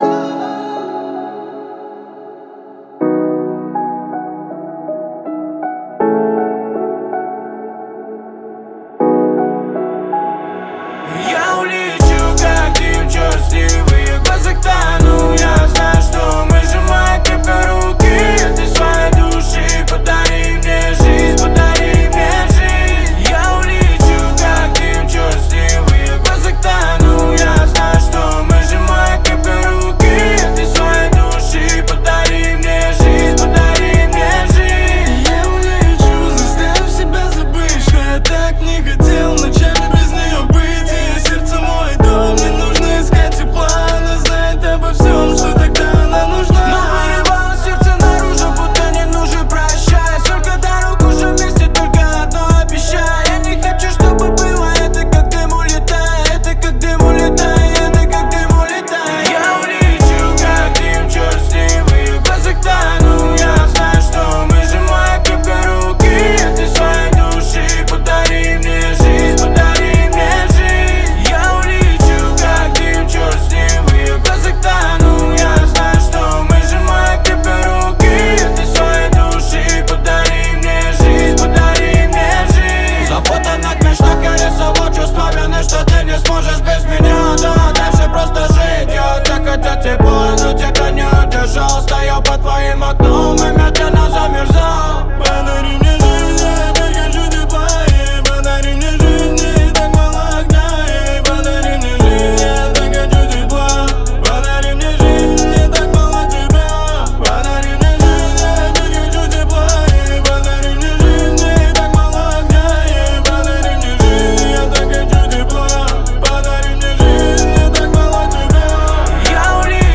это яркая и мелодичная композиция в жанре поп